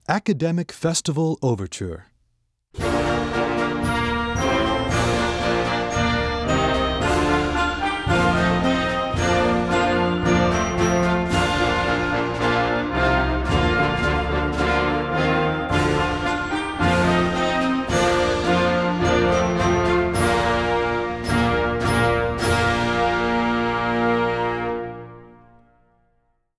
Band selections